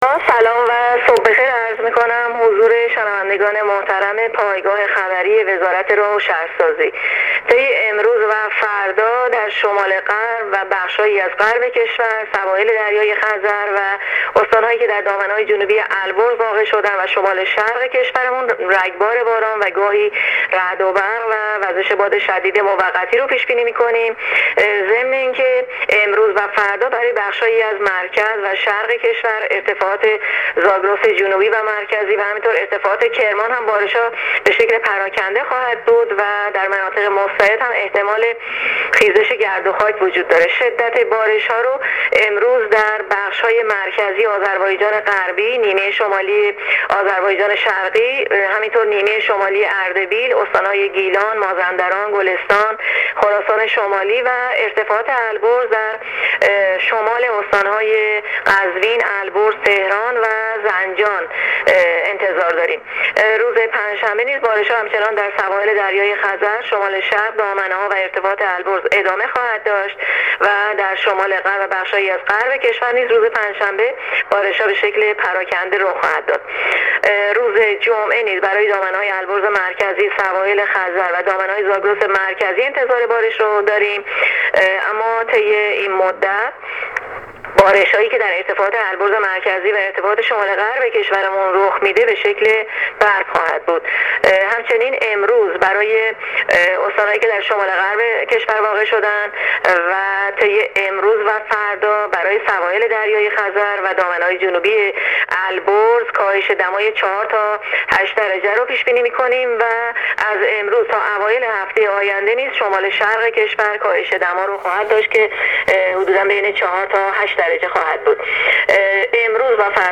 گزارش رادیو اینترنتی از آخرین وضعیت آب‌‌و‌‌‌هوای ۱۵ مهر